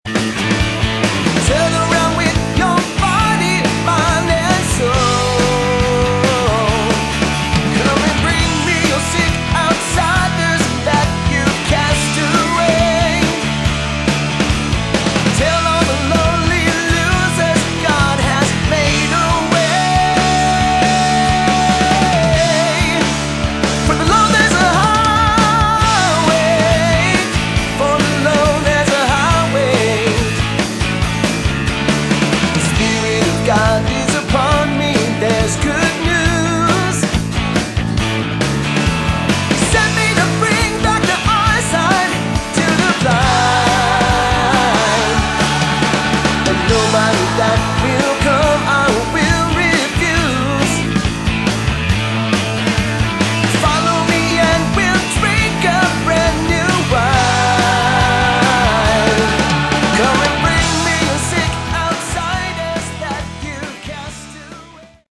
Category: Prog / AOR
keyboards, guitar
bass
drums